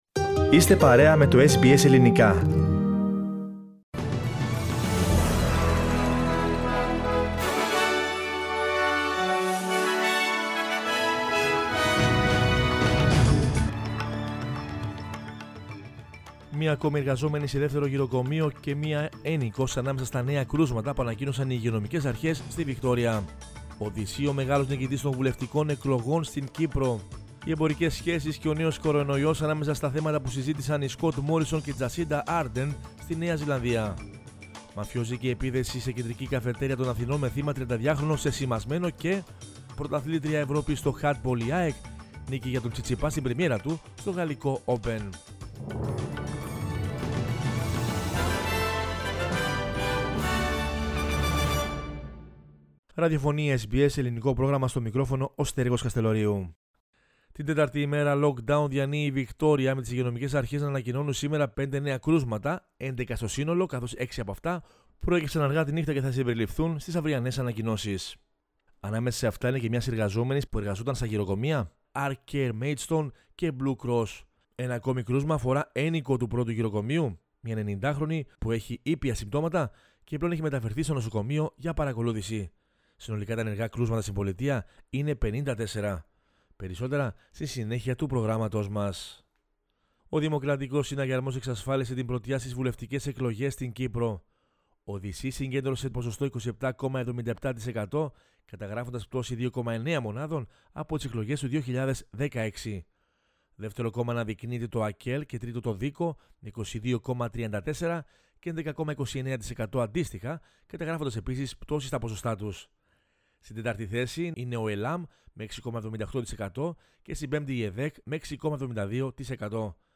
News in Greek from Australia, Greece, Cyprus and the world is the news bulletin of Monday 31 May 2021.